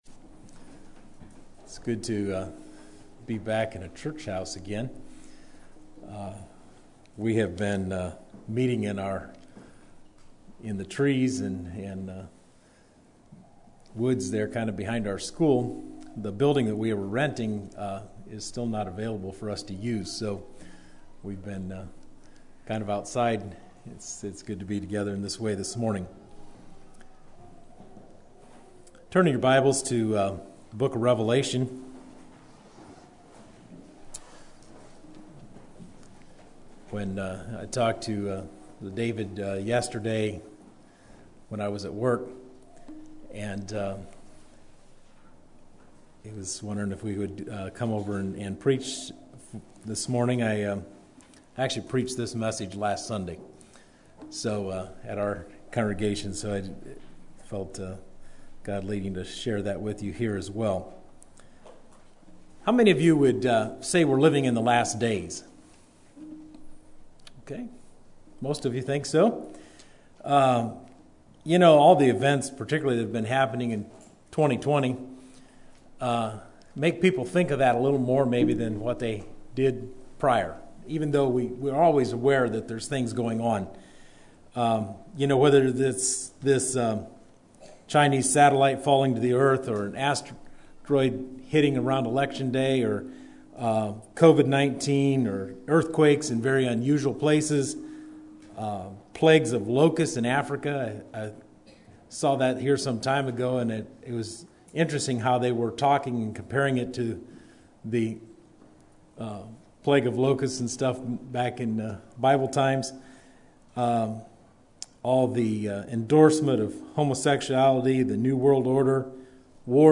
Near the end of Covid restrictions, this sermon challenges us to live prepared by knowing events happening around the world.